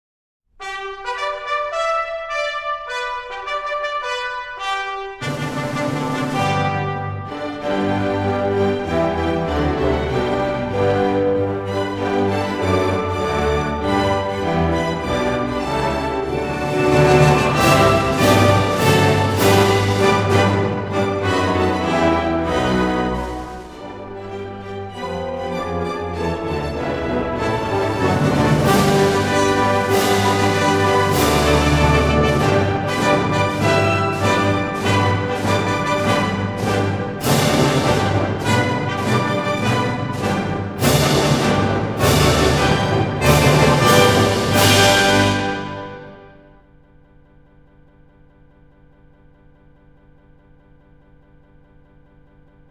中国管弦乐及小品
这张精心制作的专辑在录音、数码处理上均为上乘，既体现出管弦乐恢弘的壮丽，也体现出民乐婉转的悠扬。
弦乐器丝绸般的柔滑、管乐器光泽般的脆亮、鼓乐器礼炮般的厚重都表明该碟的分析力、空间感、动态等都有不俗表现。